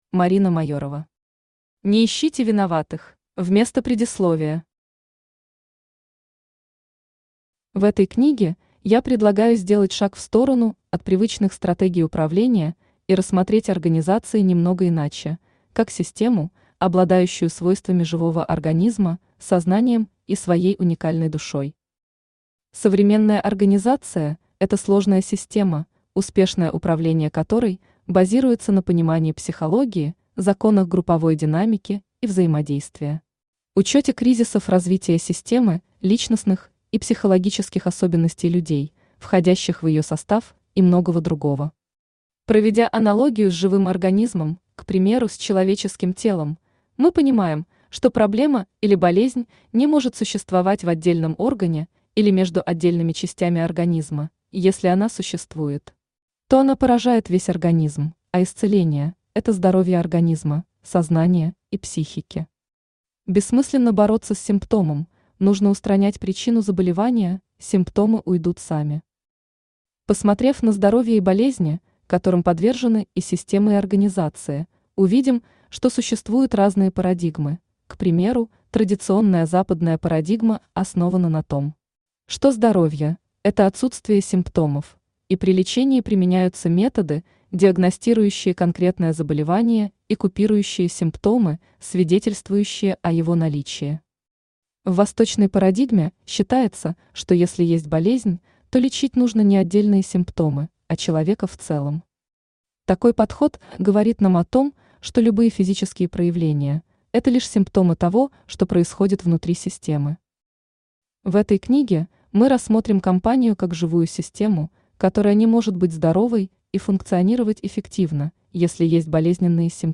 Аудиокнига Не ищите виноватых!
Автор Марина Майорова Читает аудиокнигу Авточтец ЛитРес.